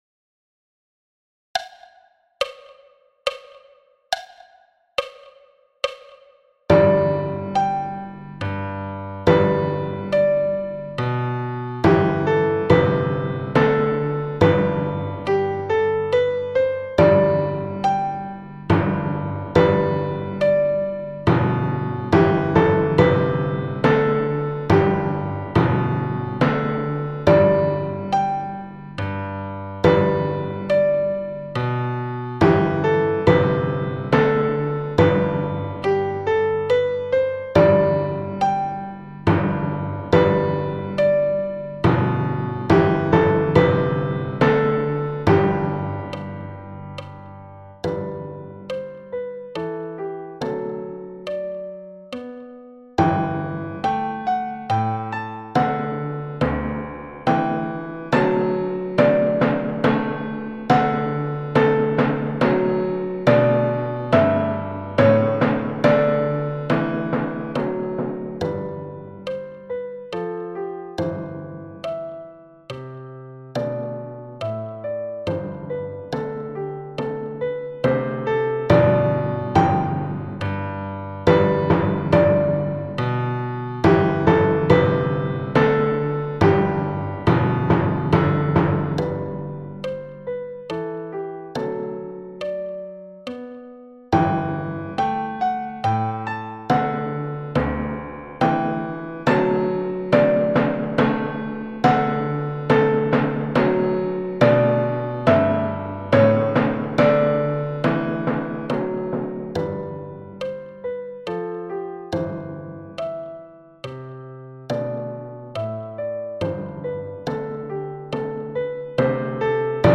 Menuet de Telemann – tutti à 70 bpm